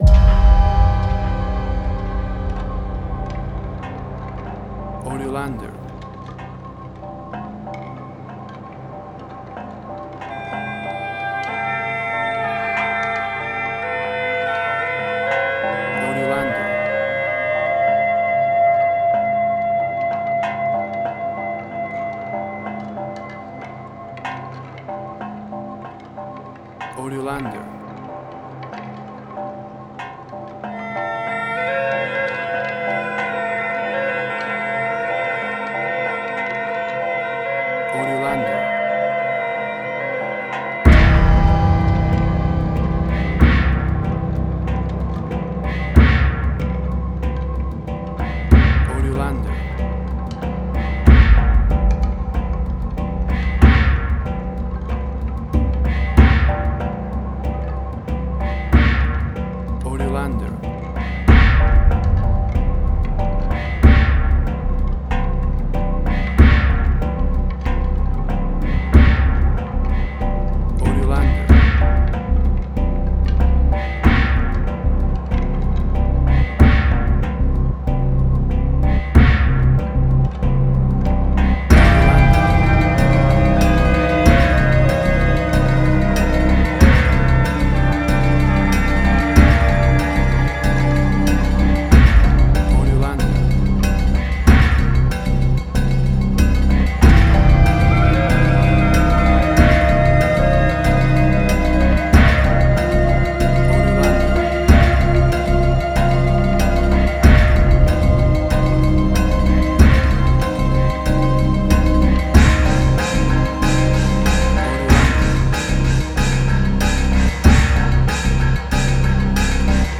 Chinese Action.
Tempo (BPM): 95